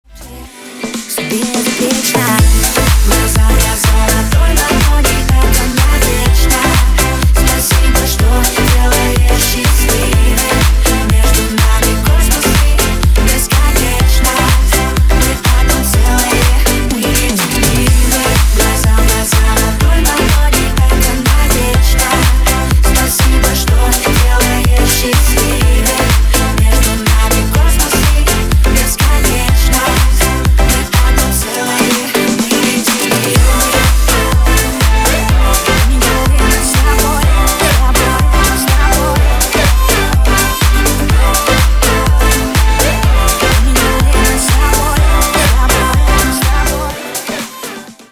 • Качество: 320, Stereo
remix
дуэт
женский и мужской вокал